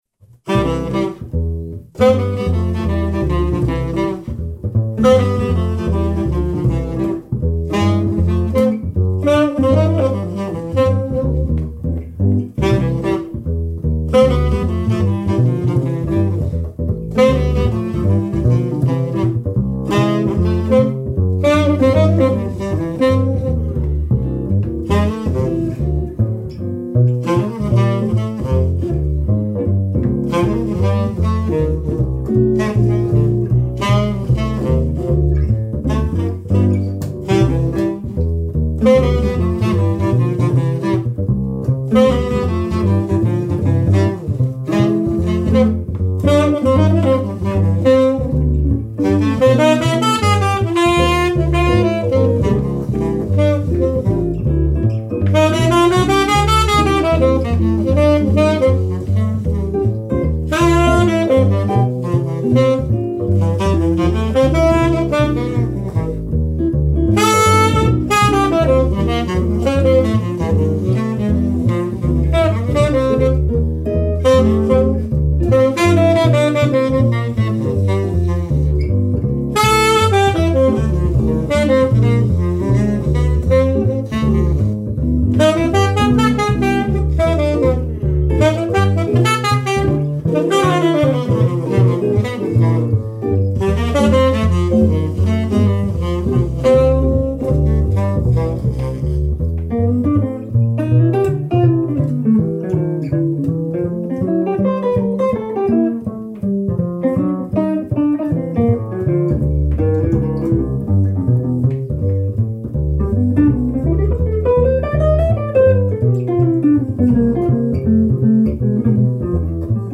Trioens speciale er den kammermusikalske side af jazzen.